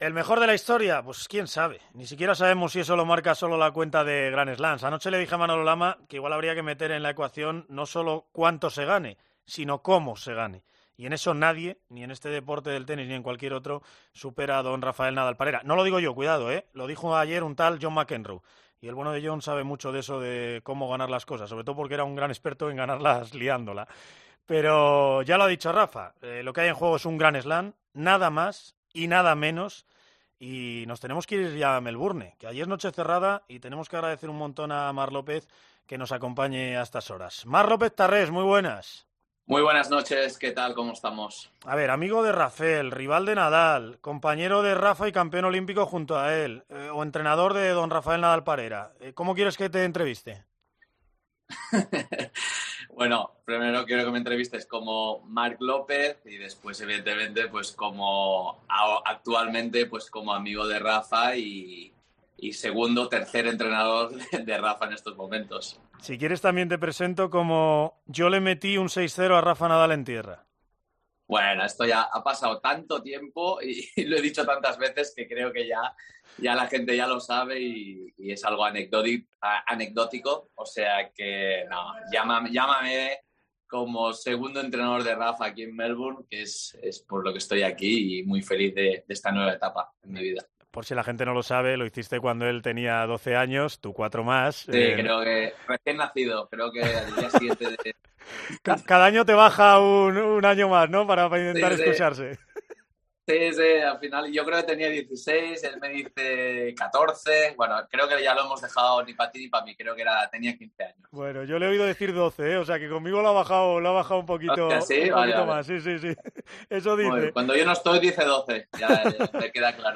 Marc López, extenista y actualmente uno de los entrenador de Rafa Nadal se pasó este sábado por los micrófonos de COPE en 'La Hora don Rafael Nadal Parera' para hablar de la final del Open de Australia donde el balear busca conseguir su 21º y convertirse en el jugador con más Grand Slam .